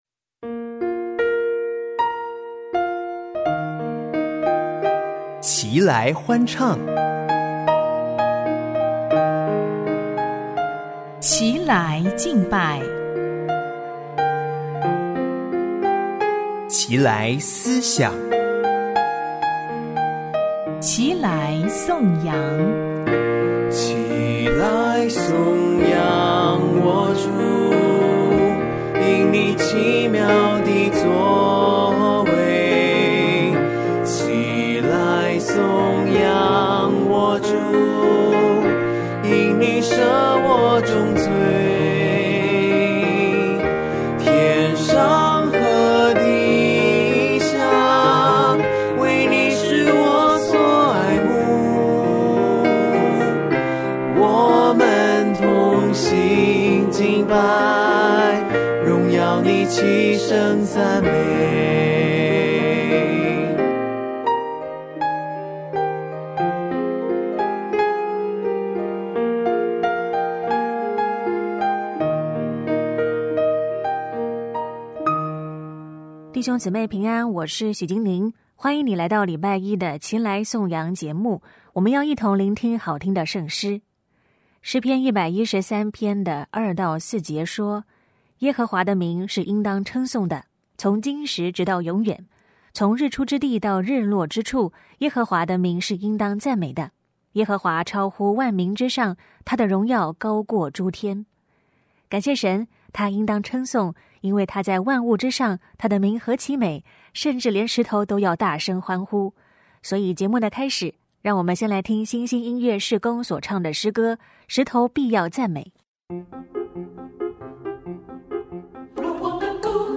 生命之歌：一起来听带领我们颂赞造物主的儿童圣诗《一切如此明亮美好》！